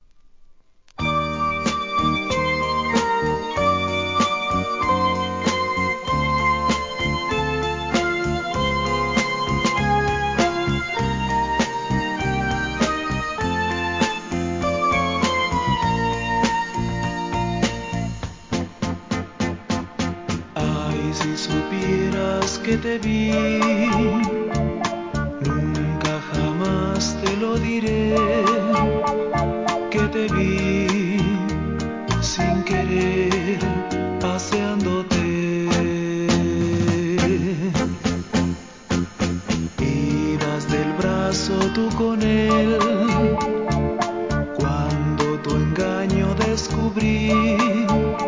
SOUL/FUNK/etc...
ラテン・ポップ